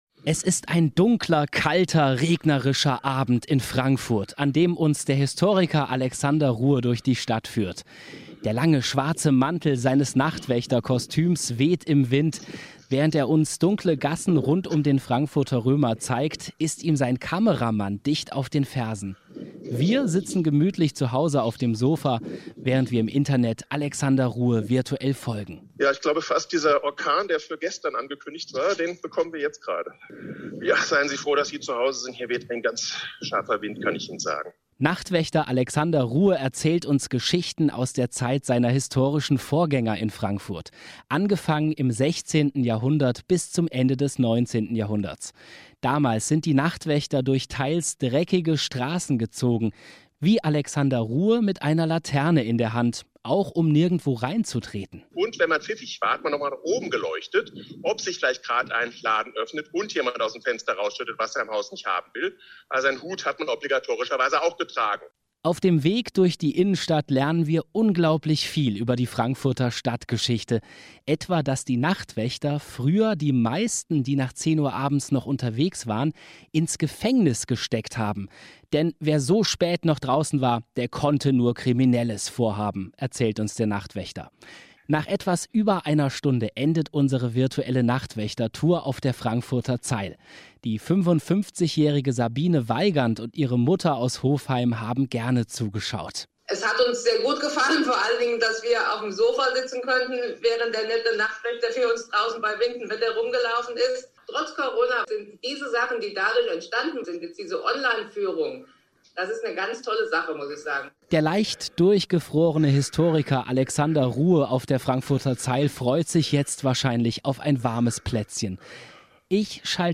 Radioreportage auf HR 1 zur Online-Nachtwächter-Führung.